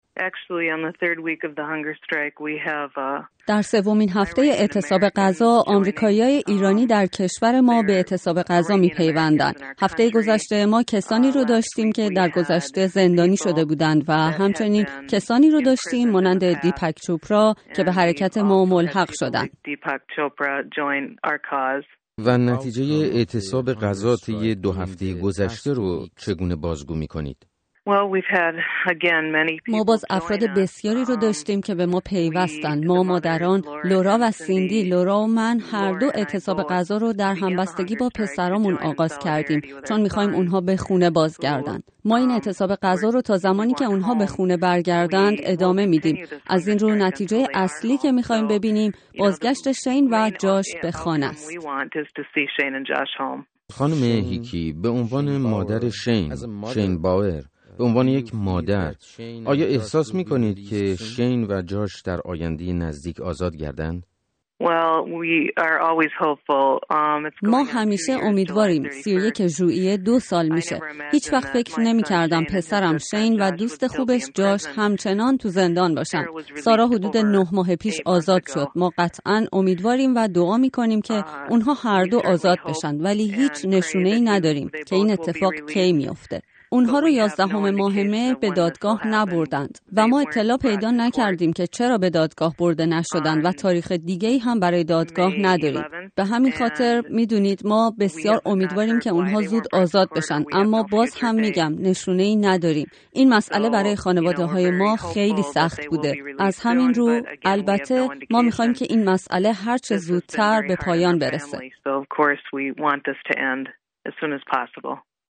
گزارش رادیویی
مصاحبه